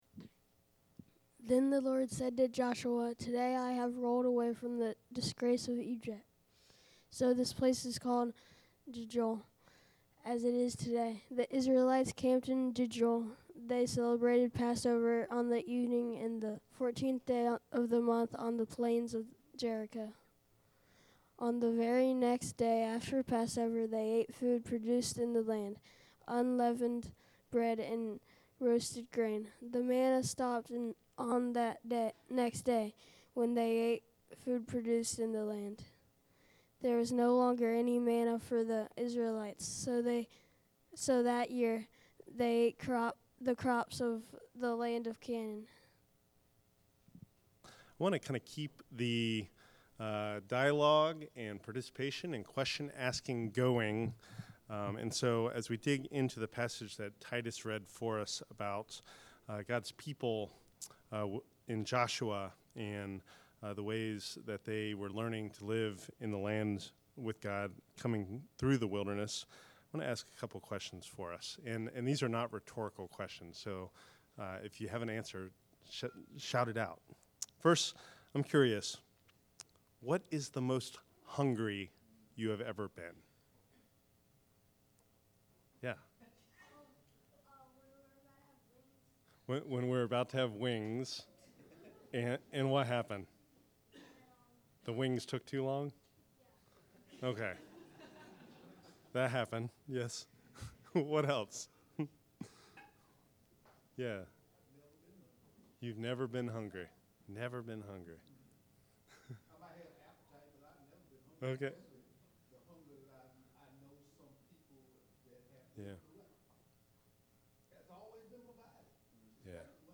Fourth Sunday of Lent